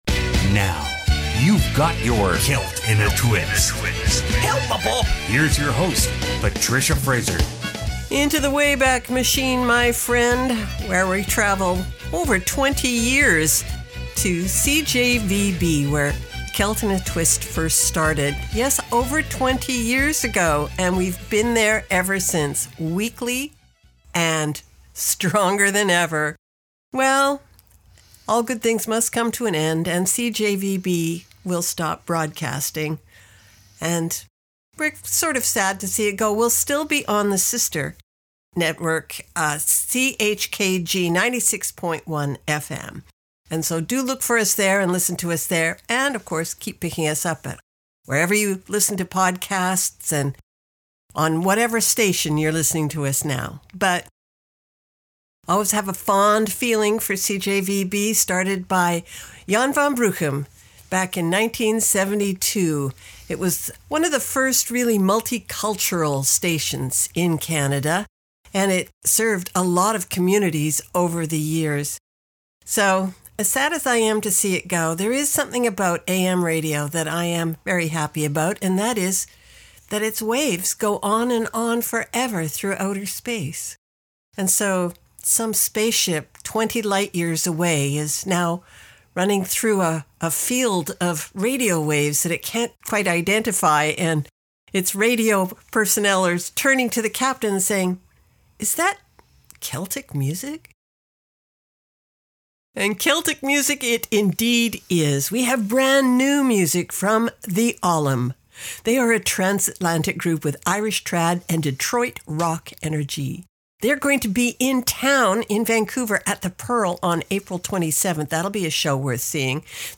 Contemporary Celtic Radio Hour